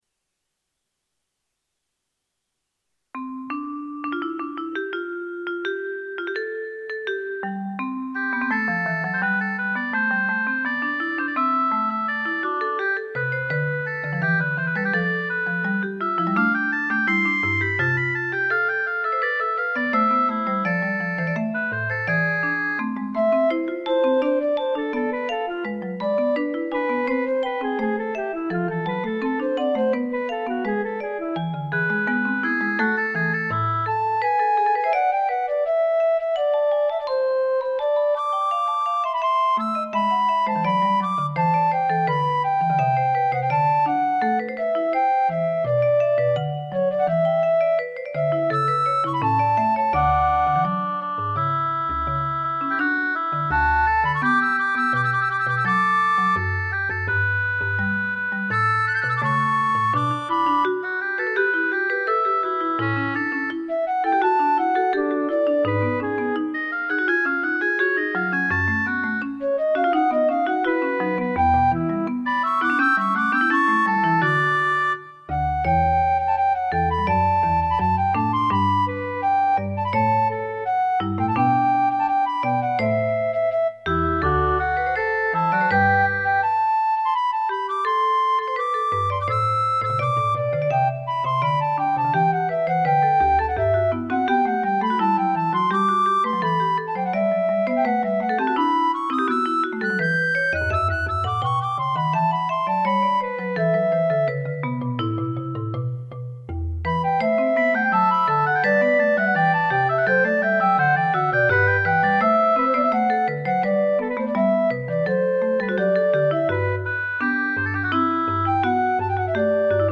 fugue played on flute, oboe and vibes and marimba.